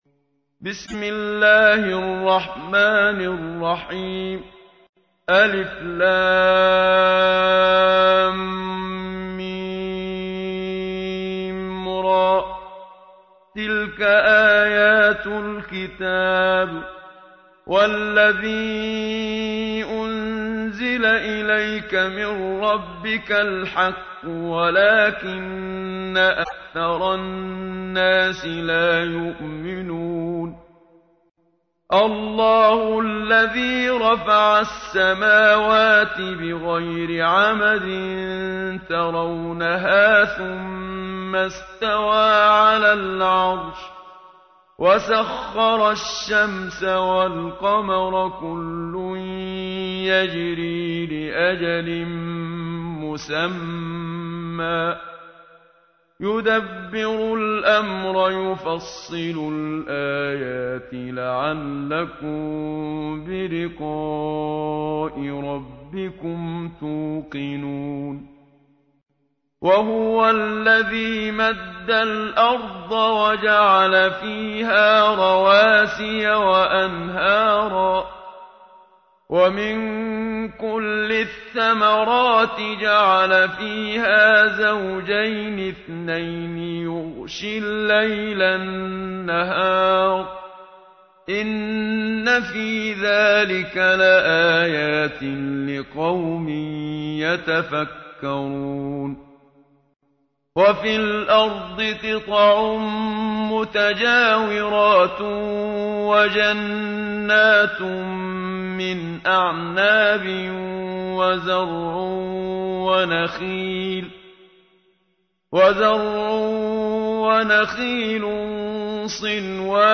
تحميل : 13. سورة الرعد / القارئ محمد صديق المنشاوي / القرآن الكريم / موقع يا حسين